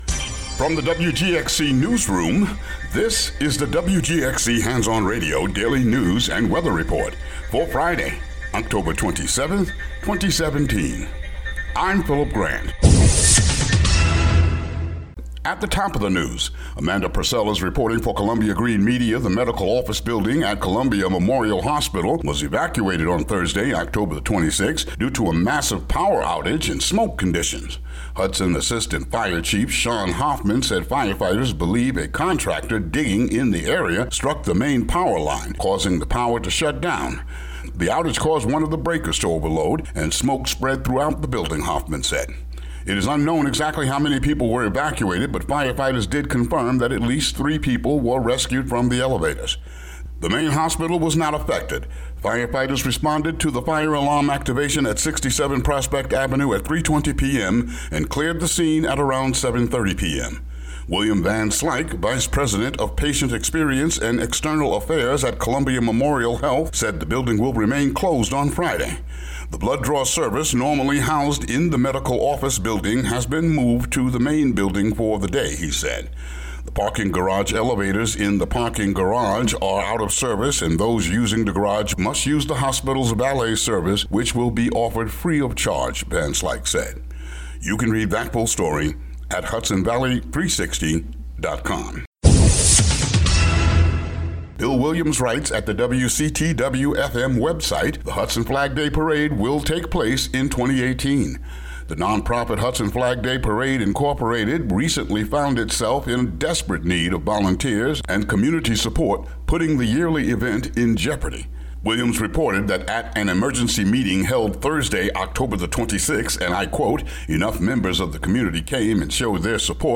WGXC daily headlines for Oct. 27, 2017.